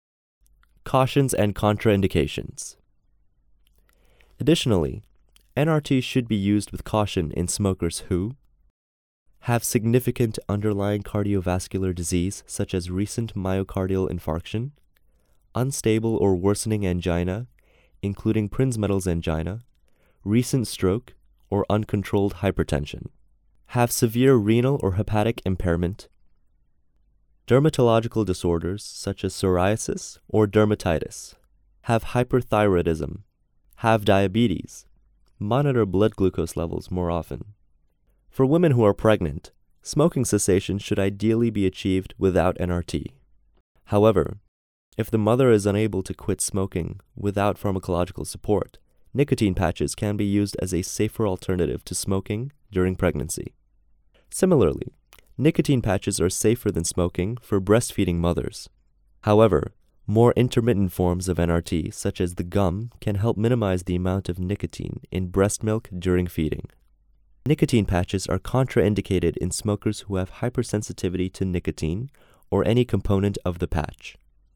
Narration audio (WAV)